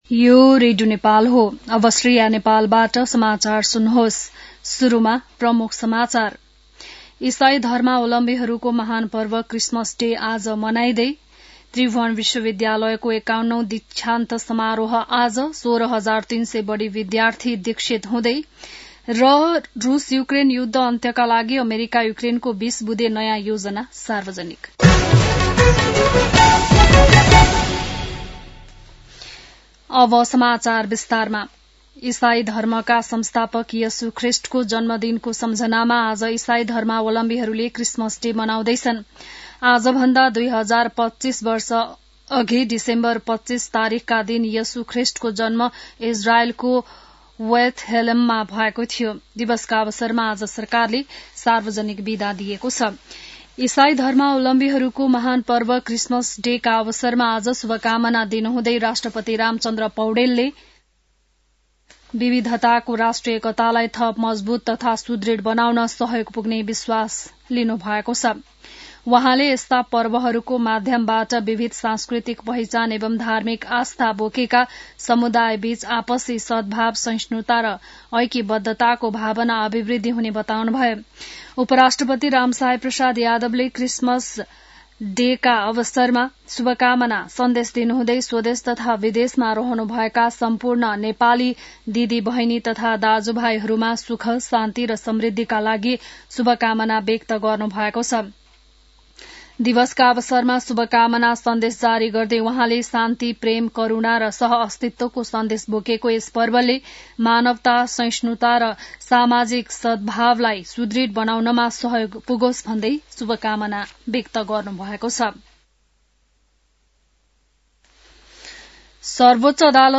बिहान ९ बजेको नेपाली समाचार : १० पुष , २०८२